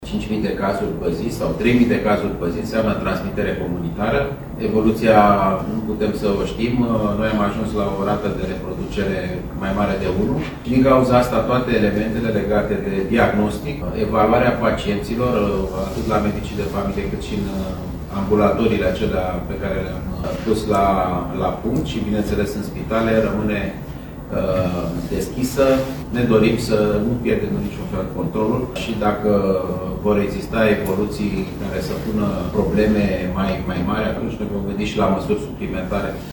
Aflat în vizită la Ploiești, Alexandru Rafila spune că răspândirea COVID-19 este încă una comunitară iar dacă situația o va cere atunci se vor lua măsuri suplimentare pentru gestionarea pandemiei: